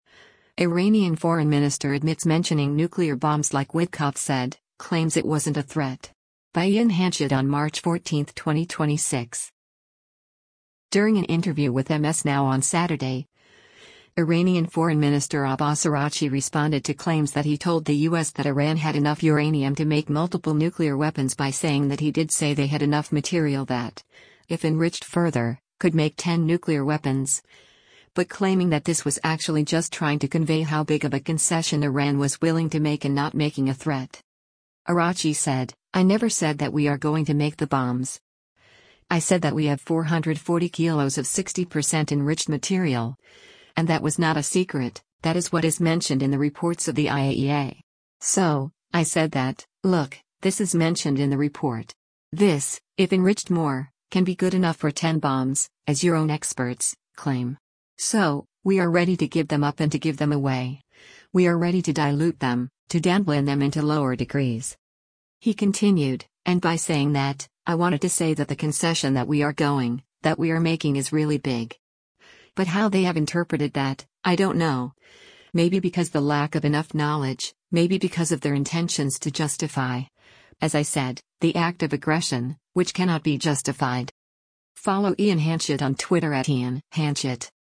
During an interview with MS NOW on Saturday, Iranian Foreign Minister Abbas Araghchi responded to claims that he told the U.S. that Iran had enough uranium to make multiple nuclear weapons by saying that he did say they had enough material that, if enriched further, could make ten nuclear weapons, but claiming that this was actually just trying to convey how big of a concession Iran was willing to make and not making a threat.